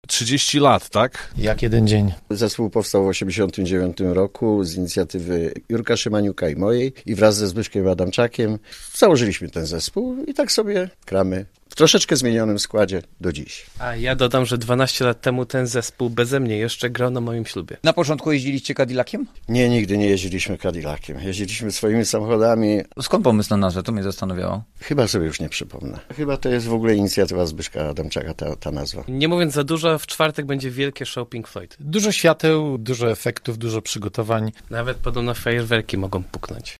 a Radio Zielona Góra odwiedziło salę prób zespołu Cadillac: